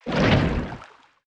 Sfx_creature_spikeytrap_pulling_01.ogg